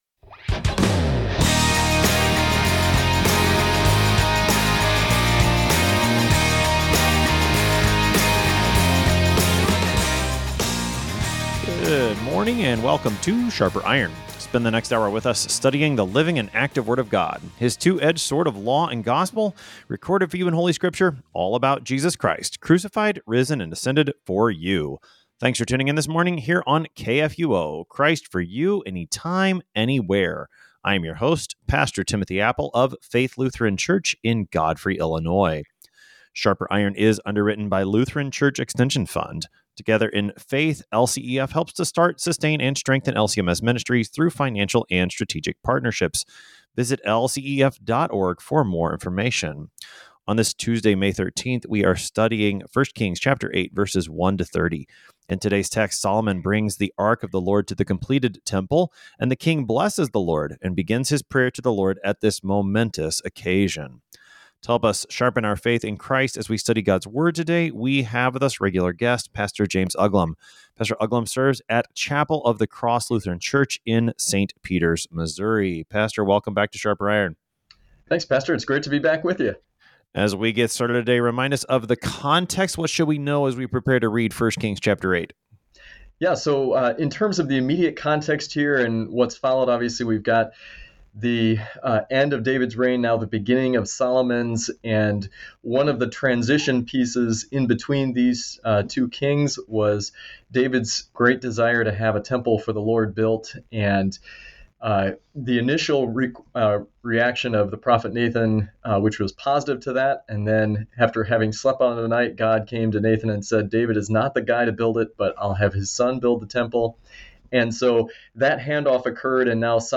Two pastors engage with Gods Word to sharpen not only their own faith and knowledge, but the faith and knowledge of all who listen.